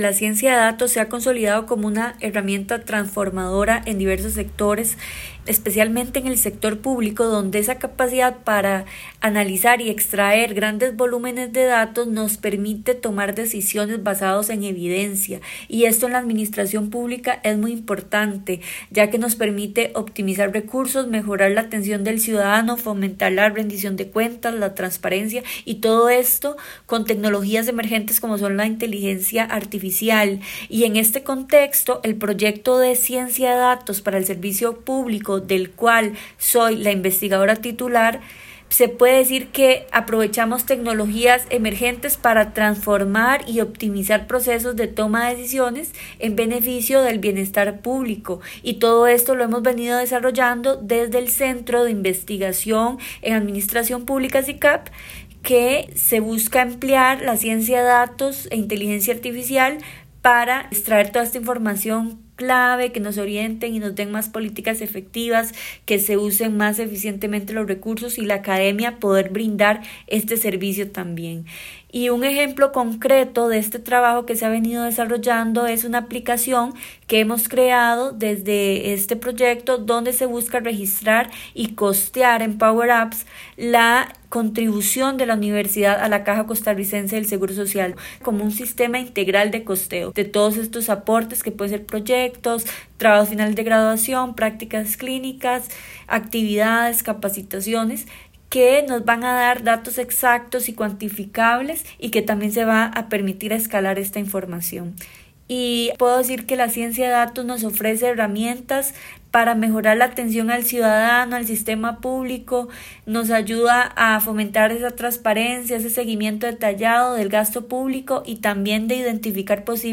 La voz de la experta: resumen del artículo